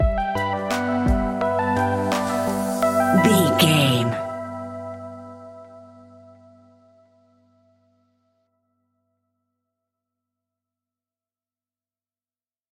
royalty free music
Aeolian/Minor
hip hop
chilled
laid back
groove
hip hop drums
hip hop synths
piano
hip hop pads